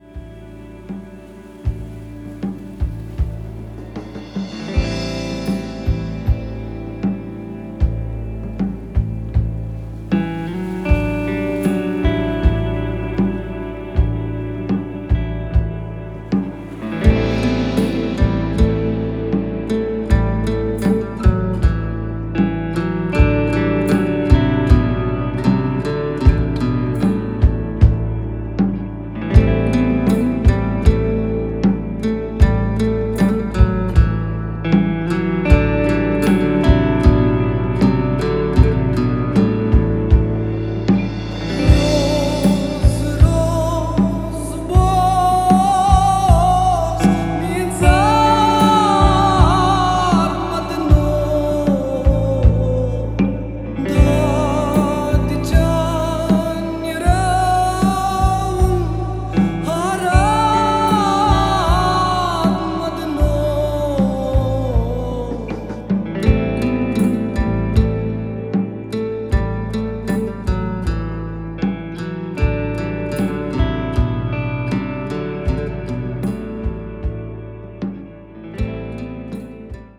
The haunting